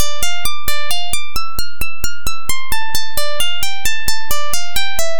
Channels: 2 (stereo)